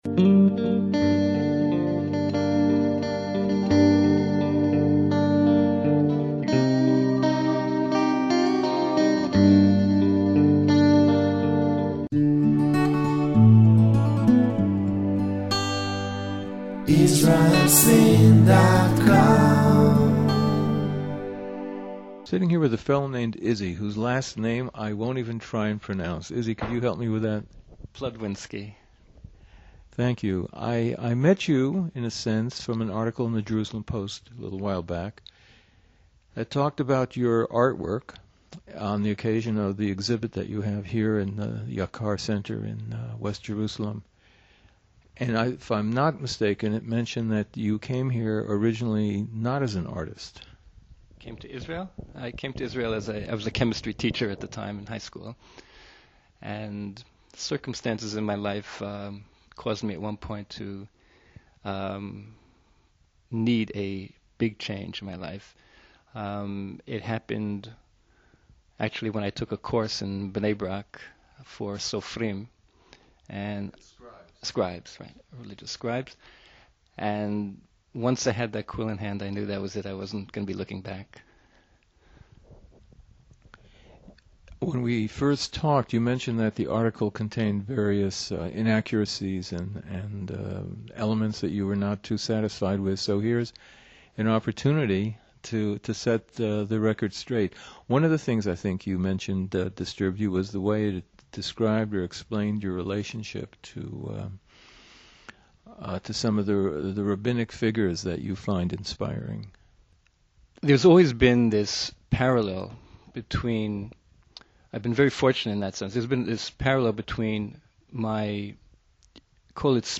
Inspiring Interview